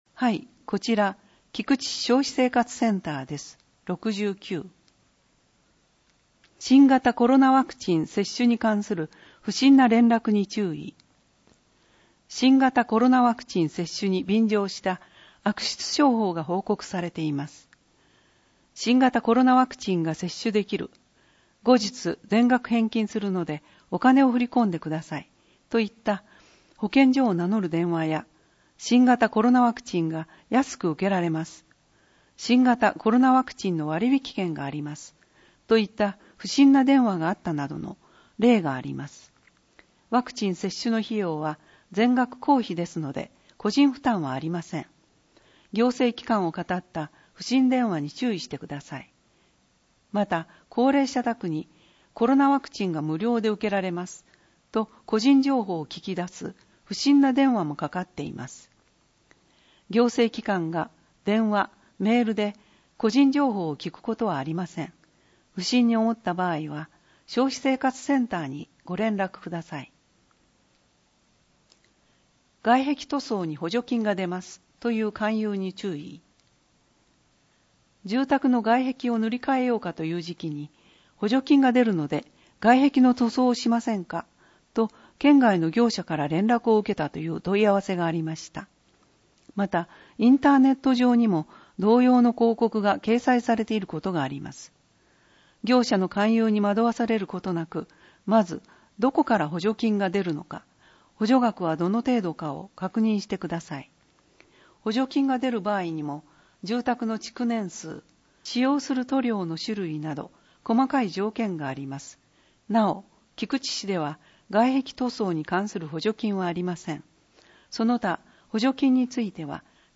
音訳
(ひこばえ輪輪会)